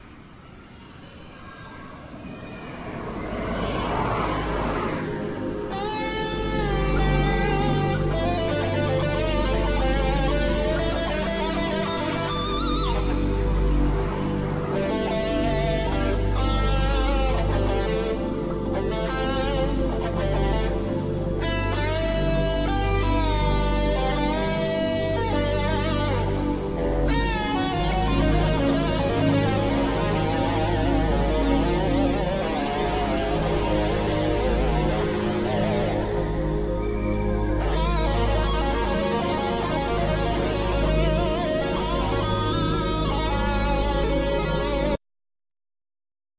Guitar(Spanish,Acoustic),Banjo,Percussions
Synthsizer,Programming
Guitar(Spanish,Electric),Percussions
Tenor saxophone